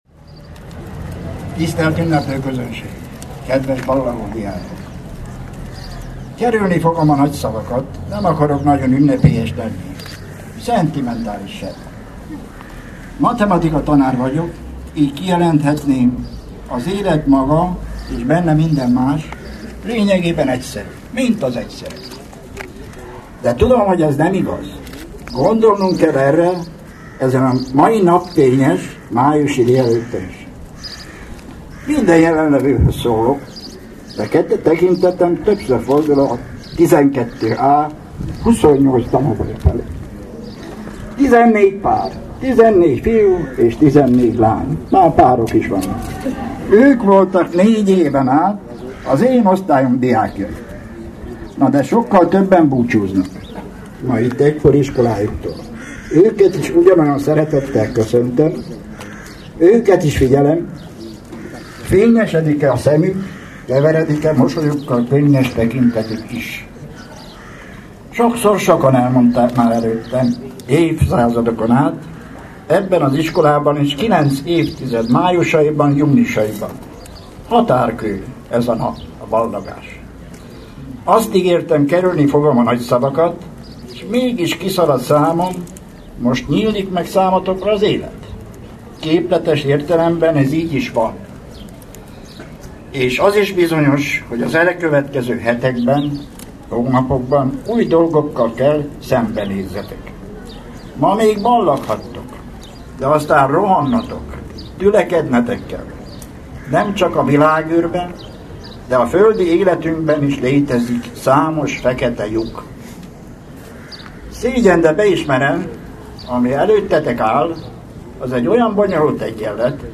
Erőteljesen kezdte, de meghatottan folytatta.